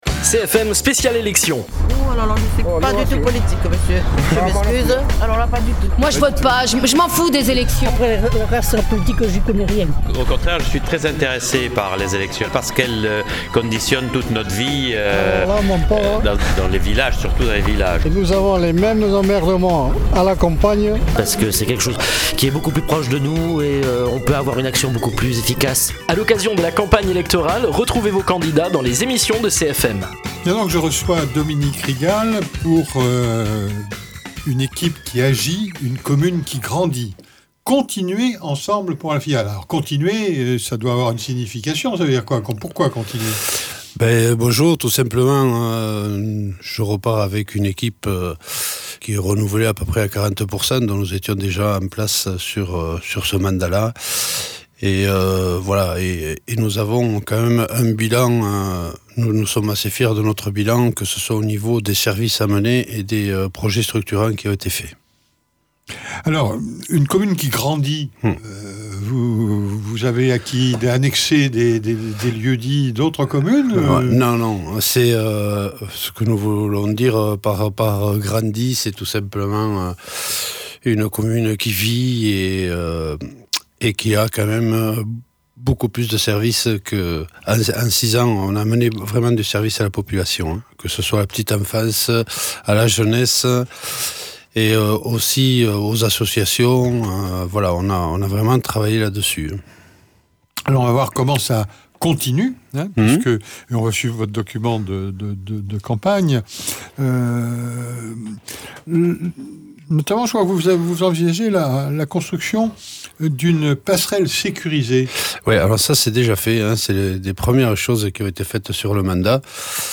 Invité(s) : Dominique Rigal, pour la liste Une équipe qui agit, une commune qui grandit.